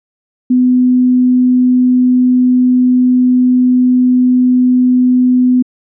1.2 音声ファイル（Wav） 波形 題材ファイル： 正弦波250Hzのデータ ( ここ から借用しました) これをダンプして眺めてみる。